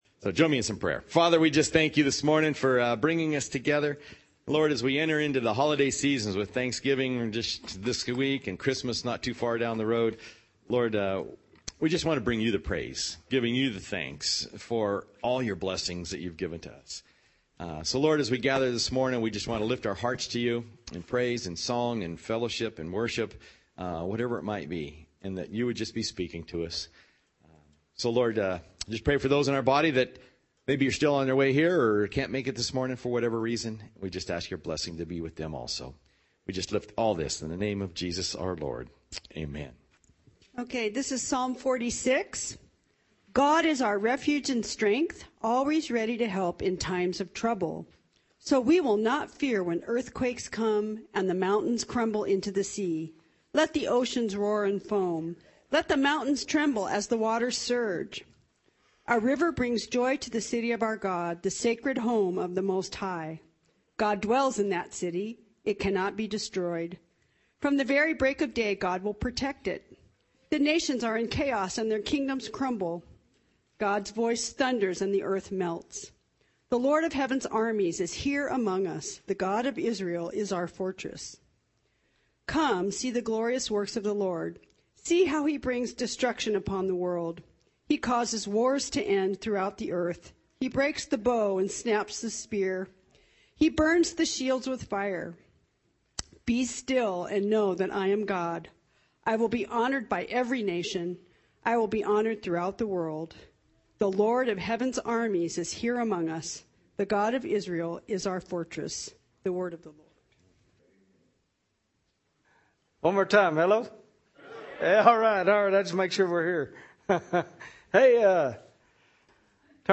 Sermons - Community Church of Loyalton